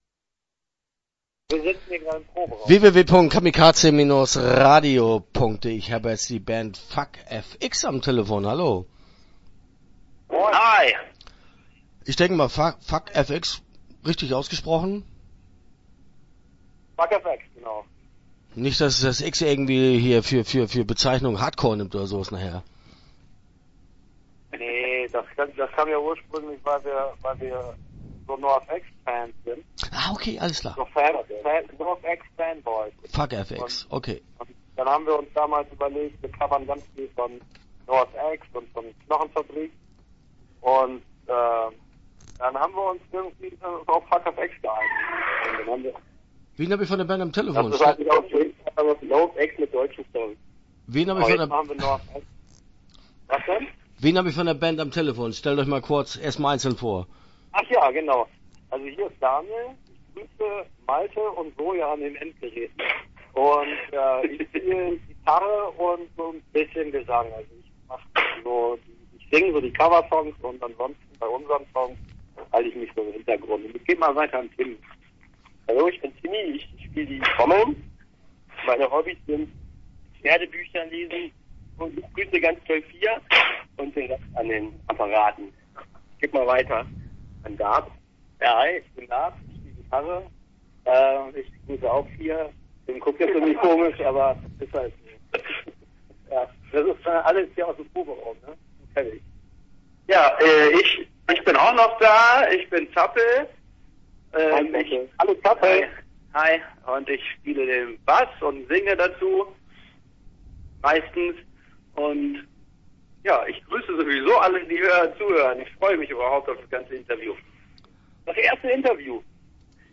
FUCKFX - Interview Teil 1 (10:36)